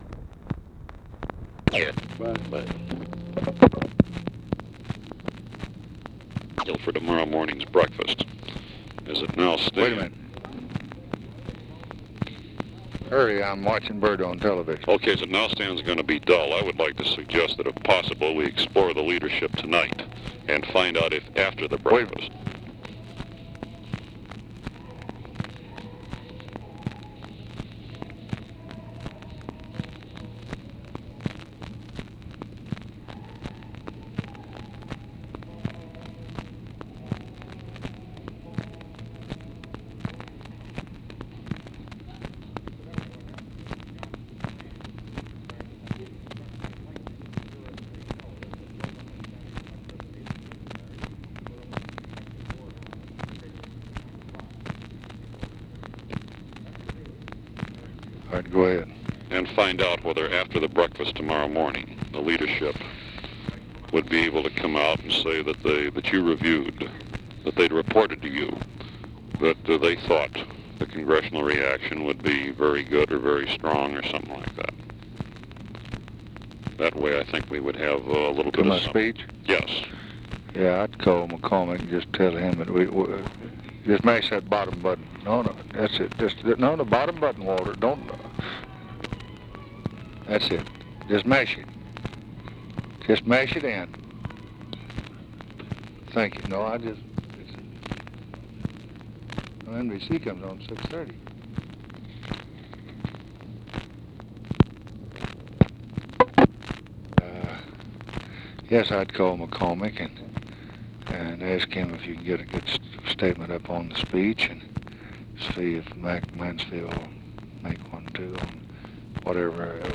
Conversation with GEORGE REEDY and OFFICE CONVERSATION, April 21, 1964
Secret White House Tapes